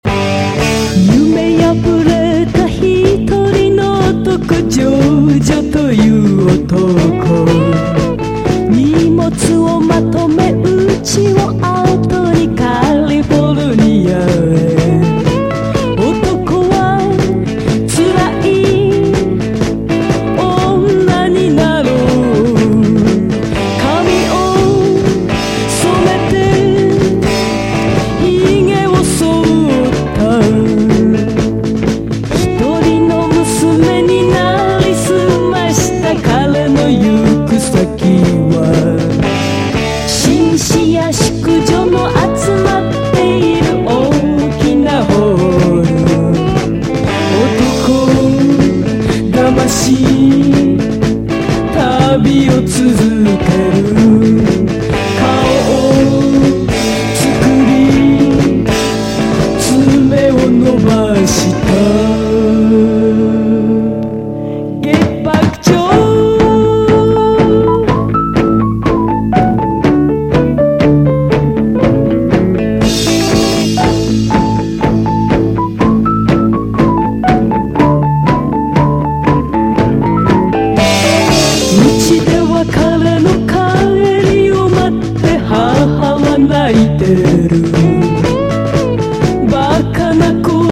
EASY LISTENING / EASY LISTENING / MOD / LOUNGE
MODなラウンジ・ソウル・カヴァー・アルバム！
シャープなストリングス・オーケストラでソウル・ナンバーをカヴァーしたファンキー・ラウンジ・プロジェクト！
ロッキン・ギターとオルガン、ホーンが絡みあうモッド・ストンパー・ラウンジ！